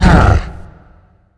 spawners_mobs_balrog_hit.1.ogg